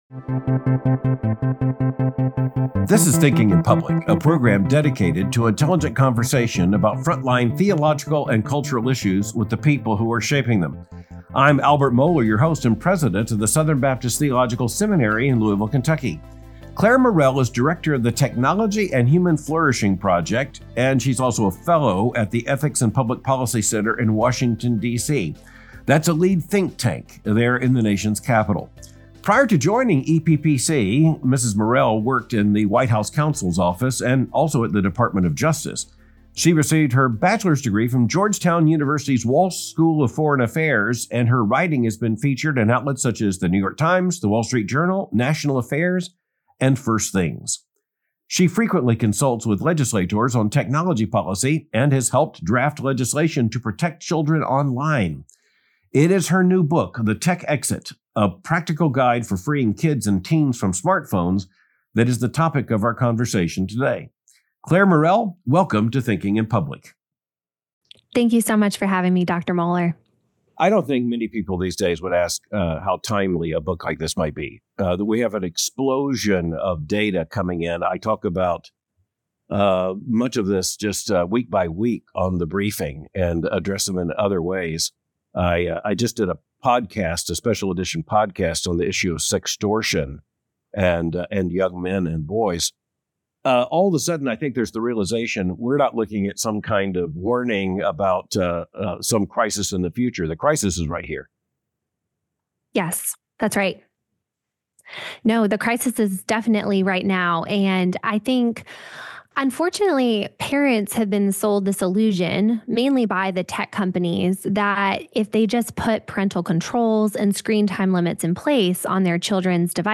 The Clash of Parenting and Social Media in a Digital Age — A Conversation